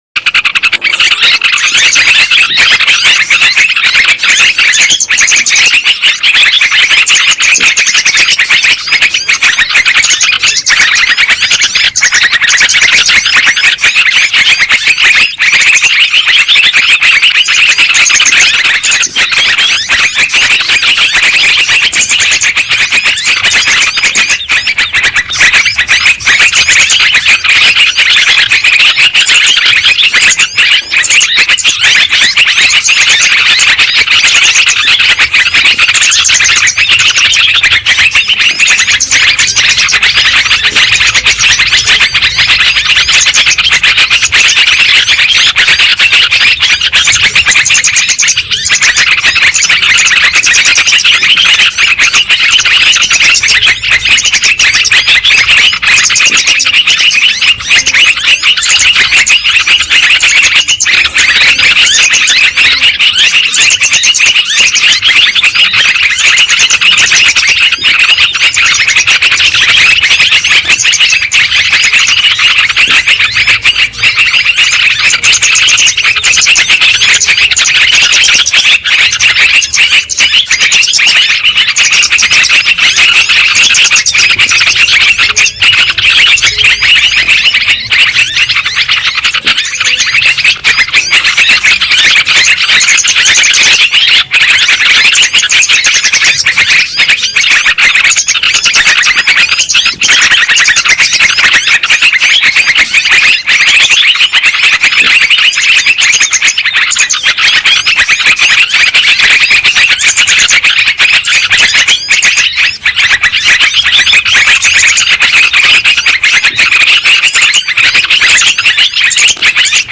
Suara Burung Ribut Mp3